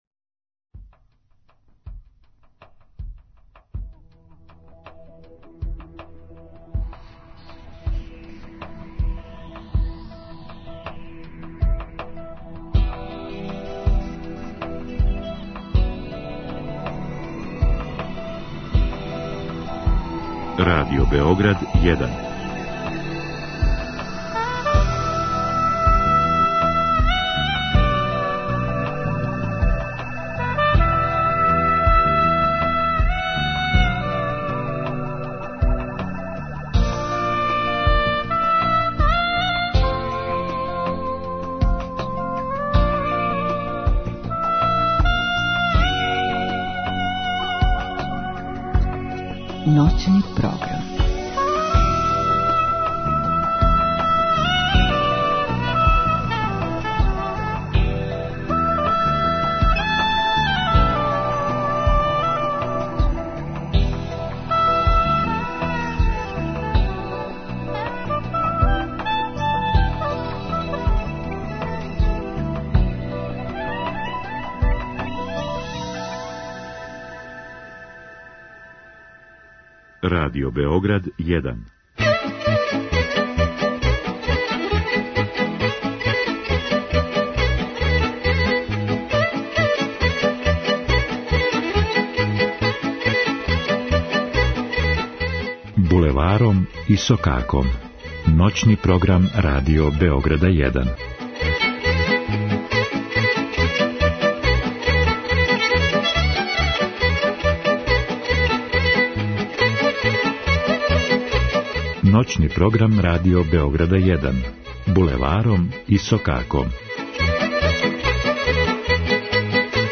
Изворна, староградска и музика у духу традиције.